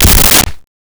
Creature Foot Step 04
Creature Foot Step 04.wav